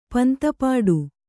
♪ panta pāḍu